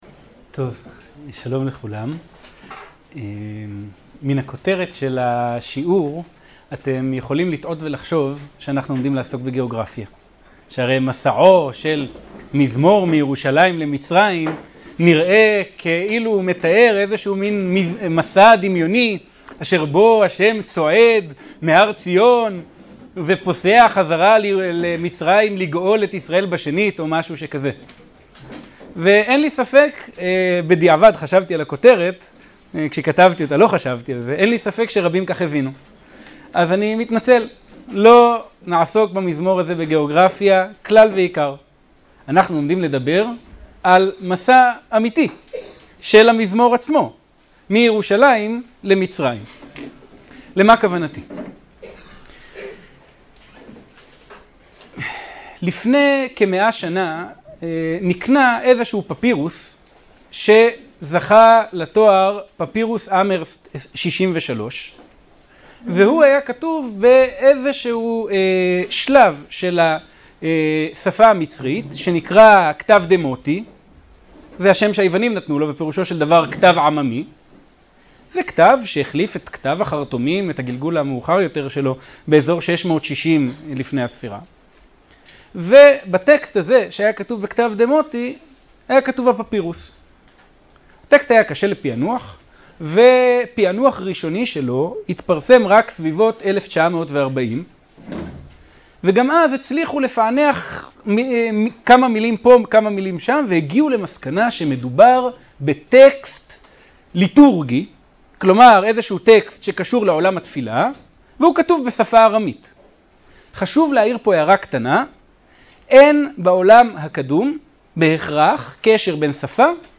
השיעור באדיבות אתר התנ"ך וניתן במסגרת ימי העיון בתנ"ך של המכללה האקדמית הרצוג תשפ"א